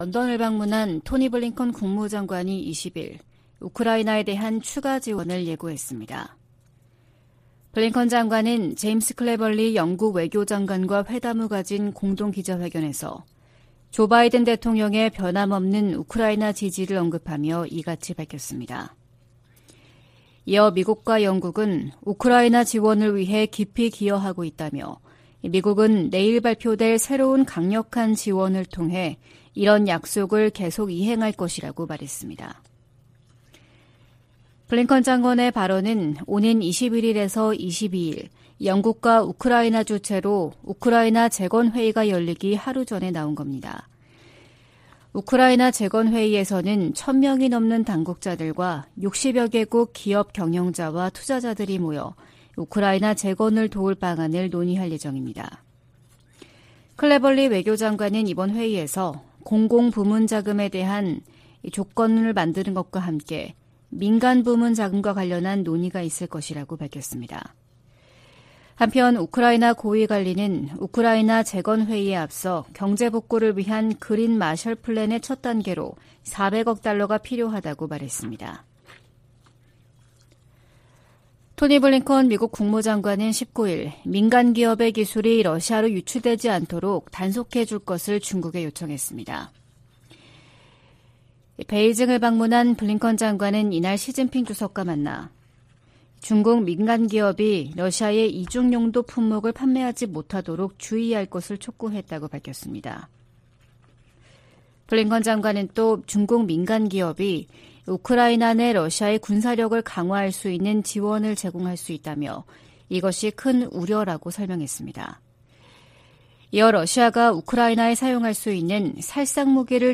VOA 한국어 '출발 뉴스 쇼', 2023년 6월 21일 방송입니다. 베이징을 방문한 블링컨 미 국무장관은 중국에 북한이 도발을 멈추고 대화 테이블로 나오도록 영향력을 행사해 줄 것을 촉구했습니다. 미 국방부 콜린 칼 차관의 최근 일본 방문은 미일 동맹의 진전을 보여주는 것이었다고 미 국방부가 평가했습니다. 한국 군은 대북 무인기 작전 등을 주요 임무로 하는 드론작전사령부를 오는 9월 창설할 예정입니다.